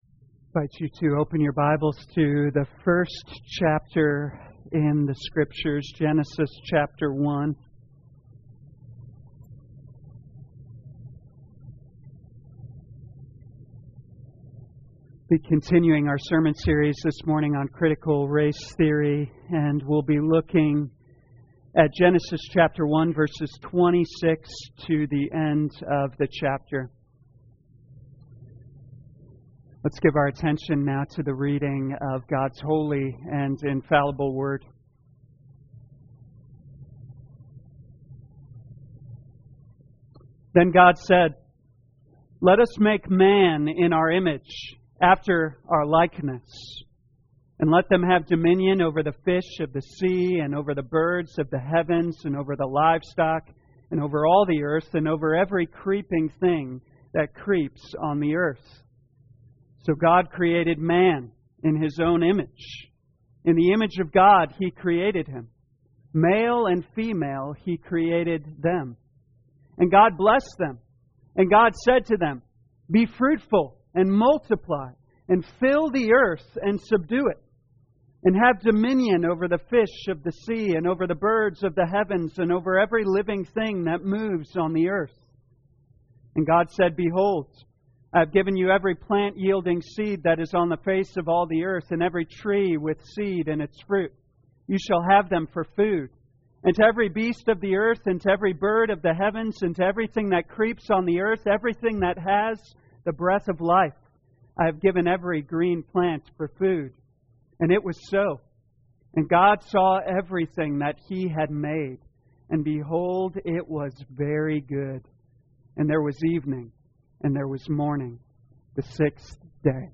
2021 Genesis Critical Race Theory Morning Service Download